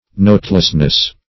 \Note"less*ness\